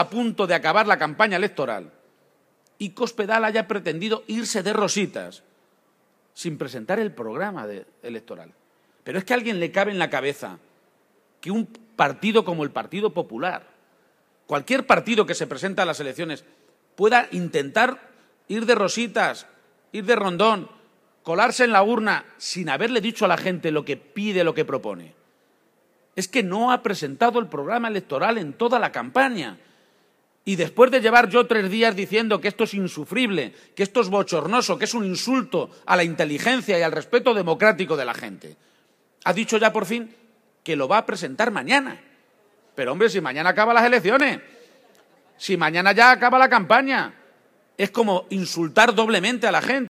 El candidato socialista a la Presidencia de Castilla-La Mancha protagonizo el mitin de fin de campaña en Albacete y anuncio que promoverá un código ético público que obligue a los partidos a presentar programa